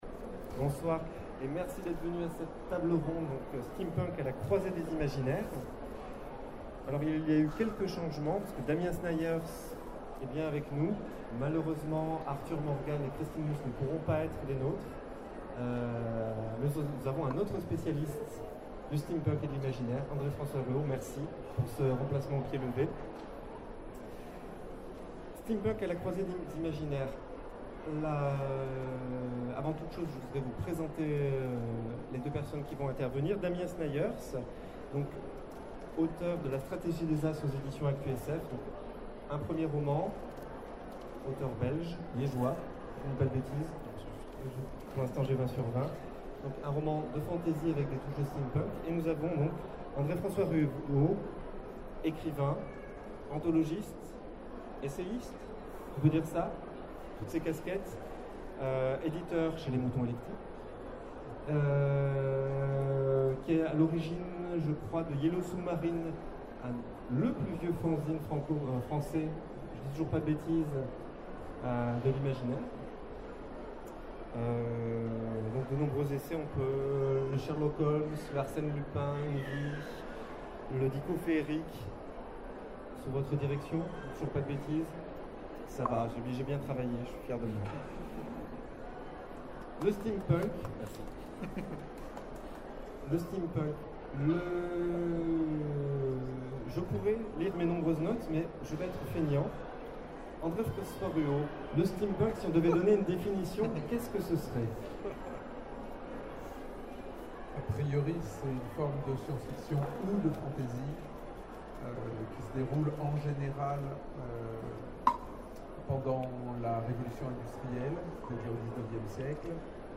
Foire du livre de Bruxelles 2017 : Conférence Steampunk : à la croisée des imaginaires